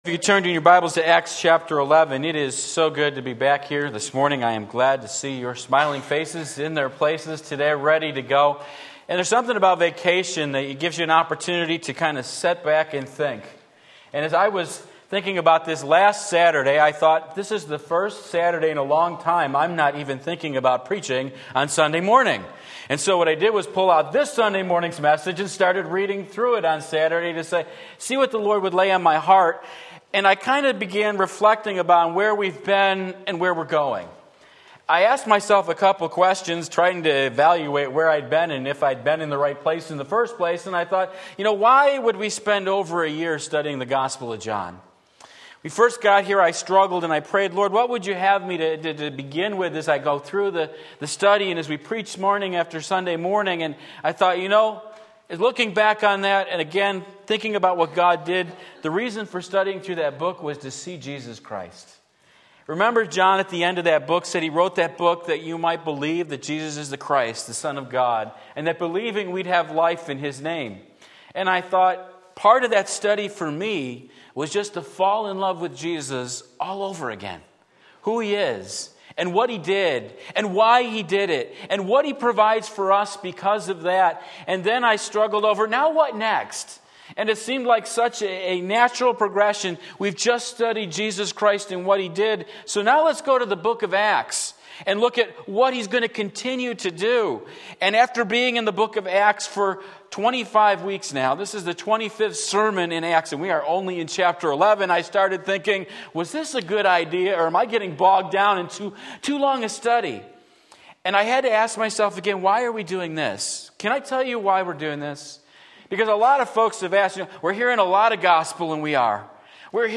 Sermon Link
The Emergence of the Gentile Church Acts 11:19-30 Sunday Morning Service